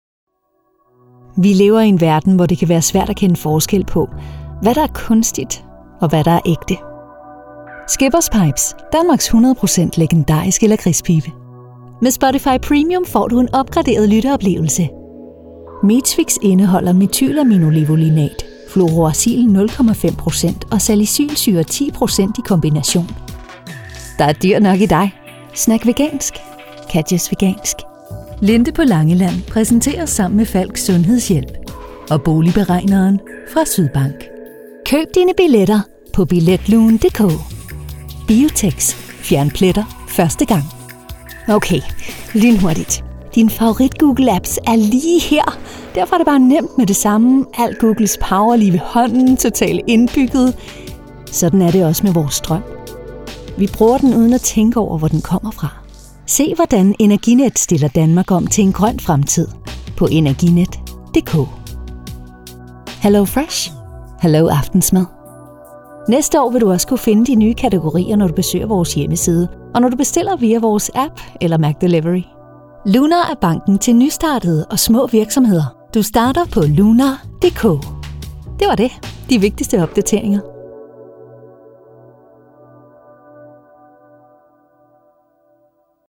Programas de televisão
Os melhores talentos profissionais de locução e atriz em dinamarquês e inglês, proporcionando a mais alta qualidade de som gravada em um estúdio profissional.